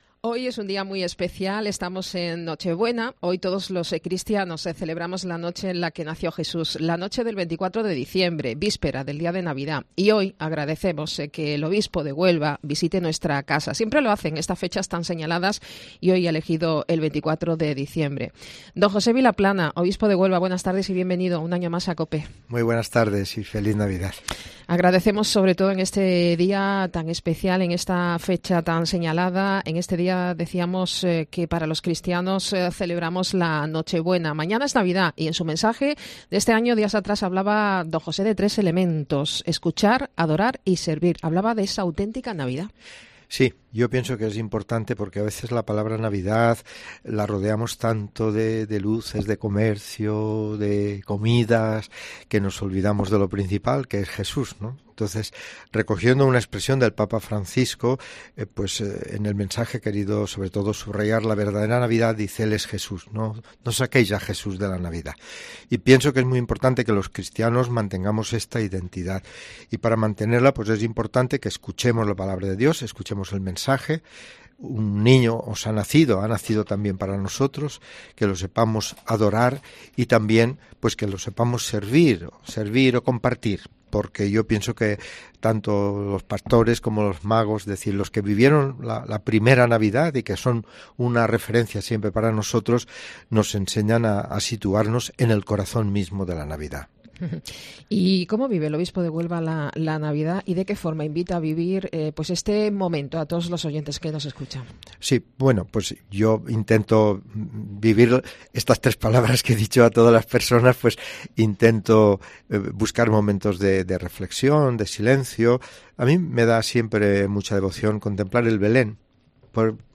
El Obispo de Huelva, Monseñor Vilaplana, en su reciente visita a nuestra emisora, ya tradicional, nos ha recordado algo esencial pero que tiende a olvidarse en nuestra sociedad y en estos días, como es el hecho incuestionable de que Jesús es el centro de la Navidad, el centro de la verdadera Navidad.